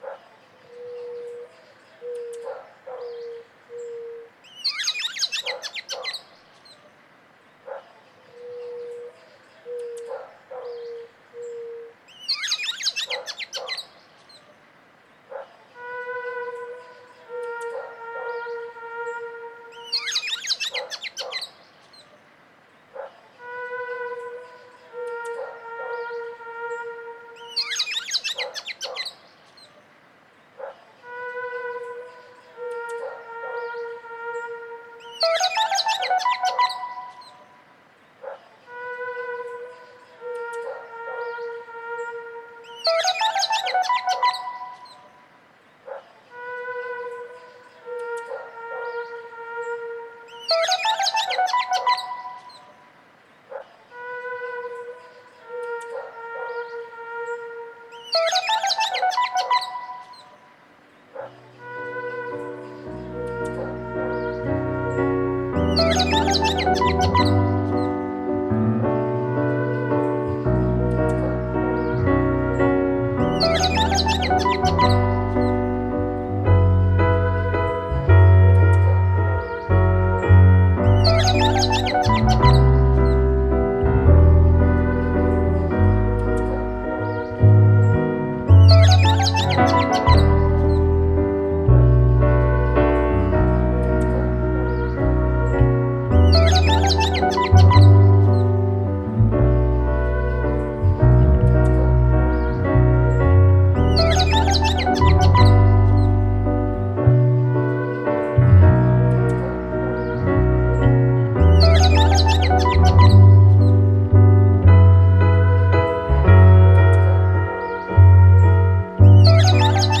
強い編集感覚で、ジャズやクラッシック、ミニマル・ミュージック的な演奏を、フィールド録音を交えて展開！